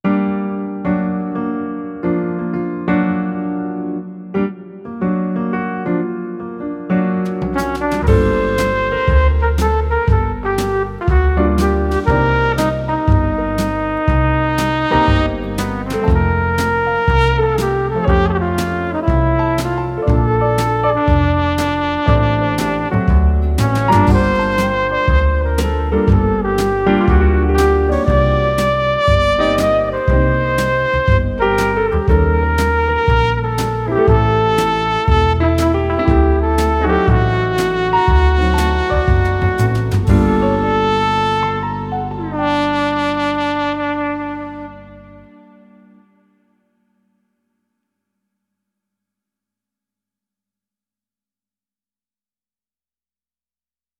вот я делал с этой дудкой и педалью экспрессии с колесом модуляции на вибрато